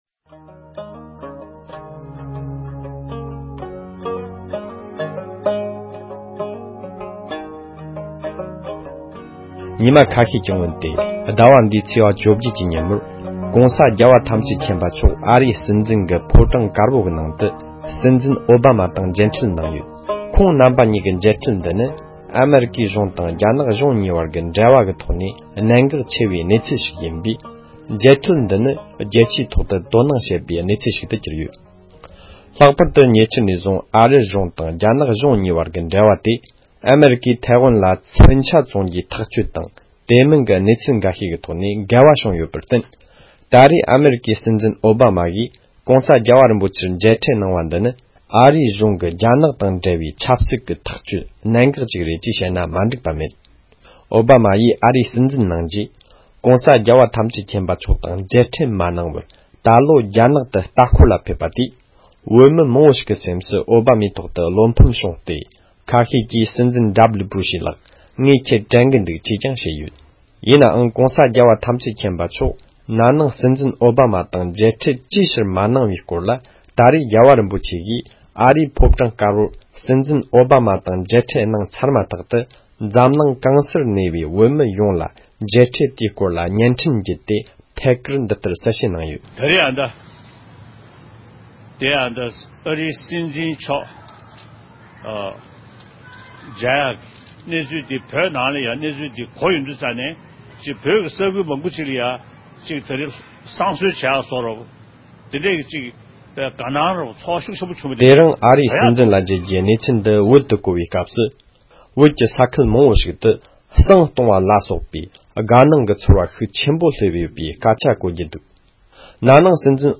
༸གོང་ས་མཆོག་དང་ཨ་རིའི་སྲིད་འཛིན་གྱི་མཇལ་འཕྲད་དང་འབྲེལ་བའི་དཔྱད་གཏམ།